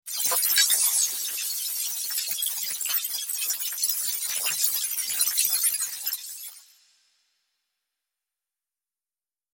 دانلود صدای ربات 47 از ساعد نیوز با لینک مستقیم و کیفیت بالا
جلوه های صوتی